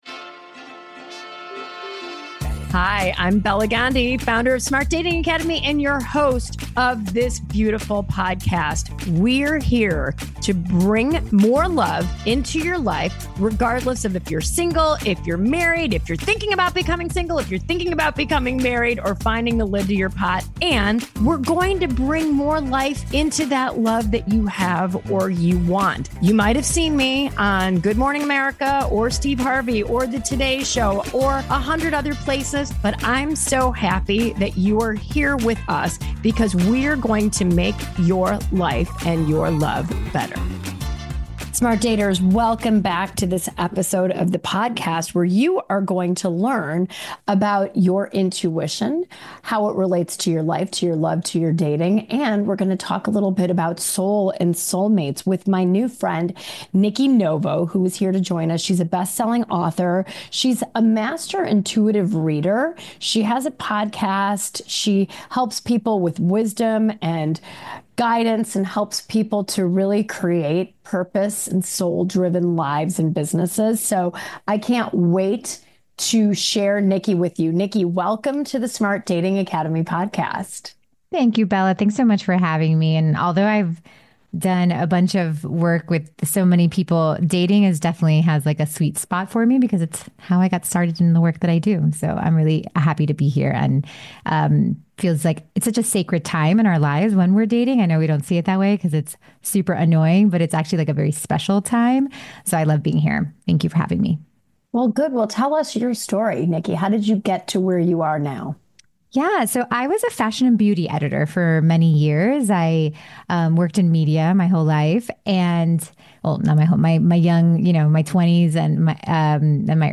In this soulful and eye-opening conversation